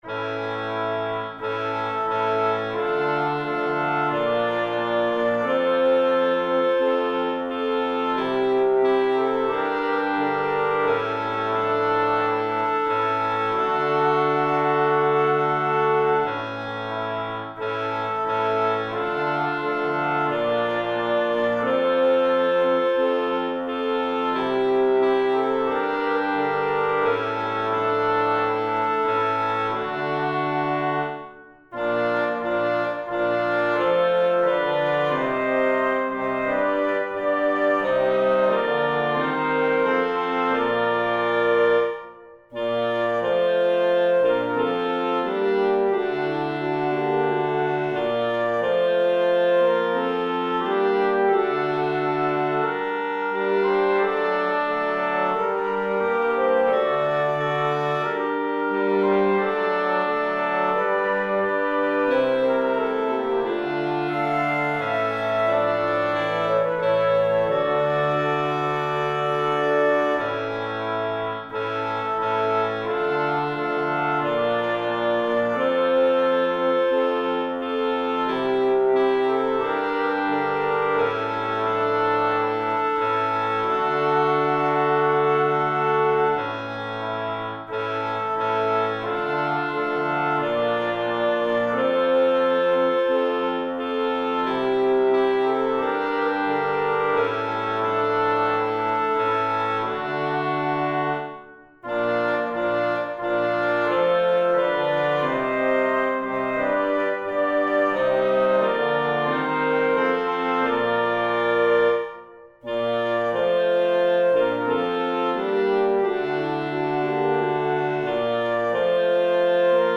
FullScore
Anthem